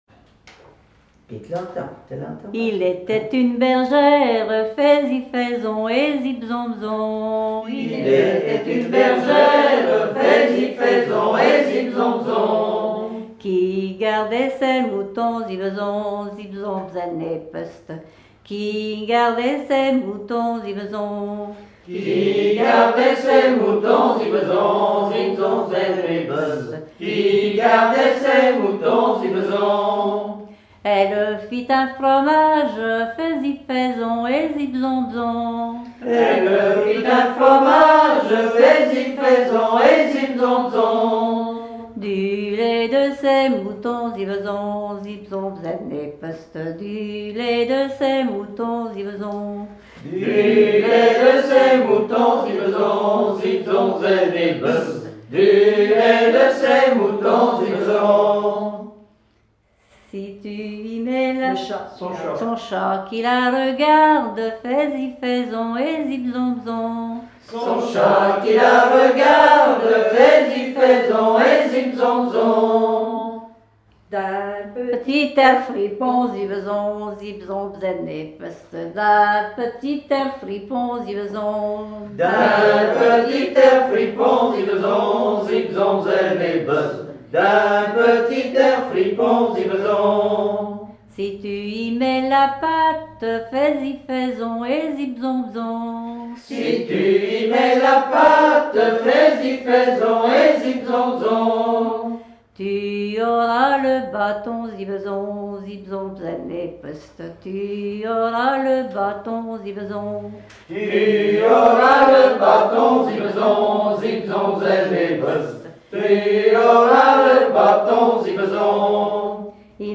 Veillées de chanteurs traditionnels
Pièce musicale inédite